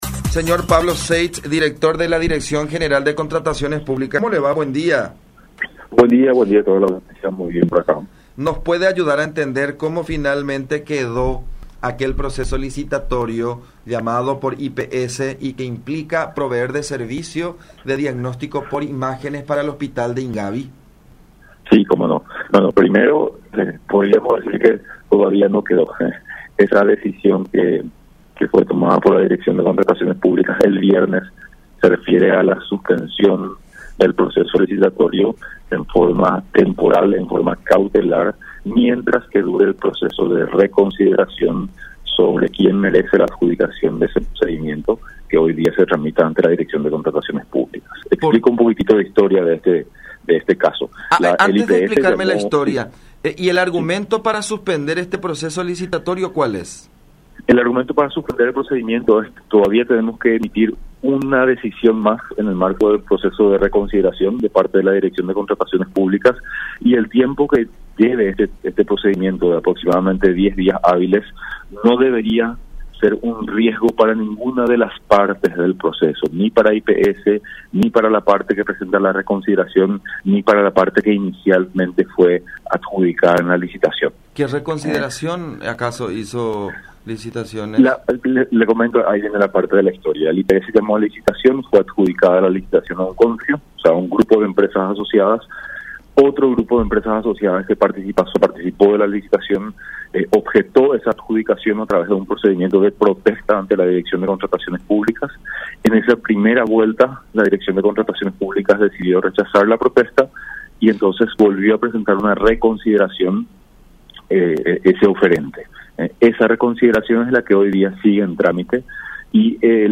“El IPS llamó a licitación, se dio la misma a un consorcio pero apareció otro objetando como se dio la adjudicación. Mientras se realice el procedimiento de consideración la suspensión del procedimiento, no entrará en vigencia el servicio”, explicó Pablo Seitz, titular de la DNCP, en contacto con La Unión.
05-Pablo-Seitz-Director-de-la-DNCP-sobre-licitaciones-de-servicios-de-imágenes-en-el-IPS.mp3